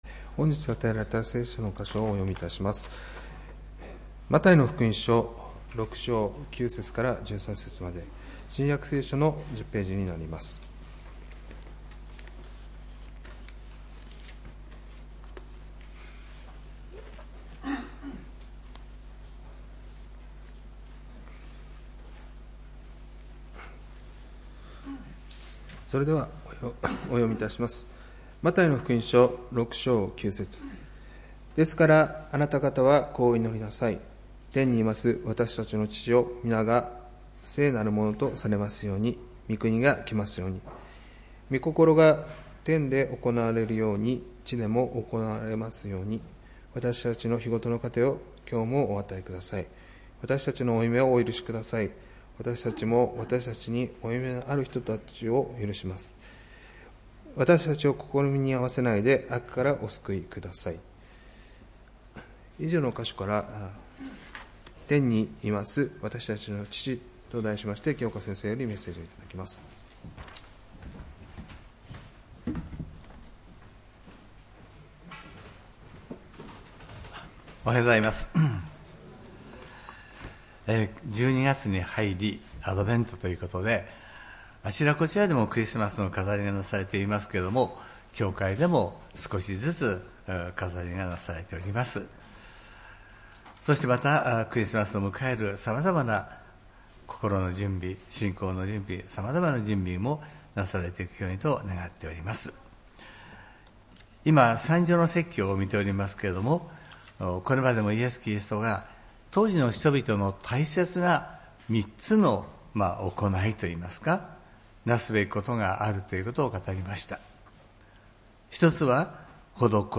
礼拝メッセージ「天にいます私たちの父」(12月１日）